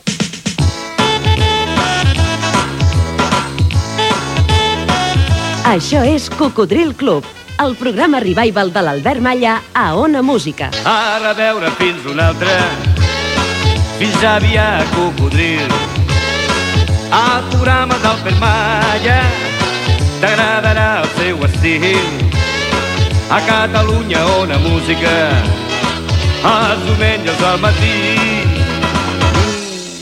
Jingle d'inici del programa
Musical
FM